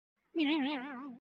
Download Funny Spring sound effect for free.